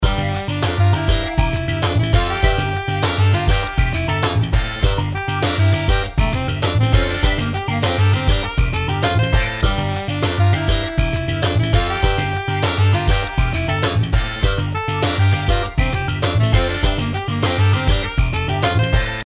音楽ジャンル： ロック
楽曲の曲調： MIDIUM
シリアス  ワクワク